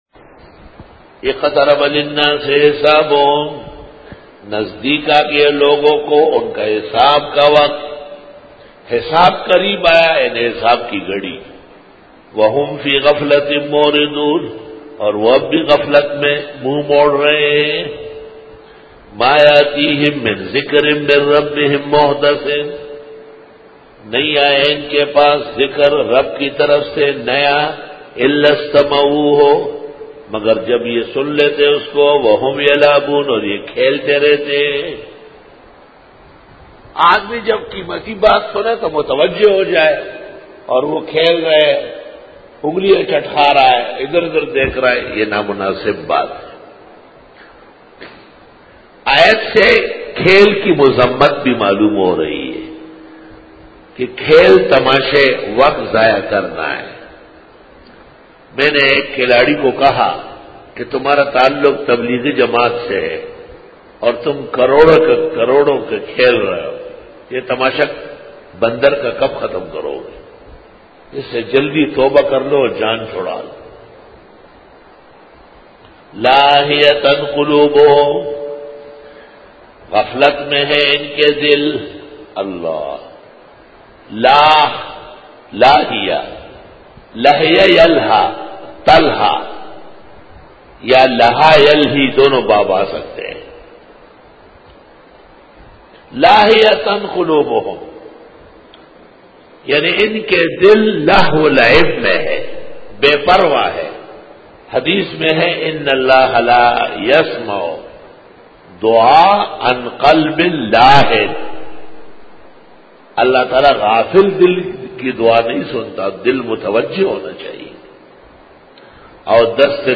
Dora-e-Tafseer 2012